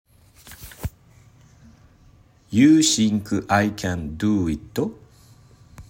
通常カタカナ ユー　シンク　アイ　キャン　ドゥー　イット？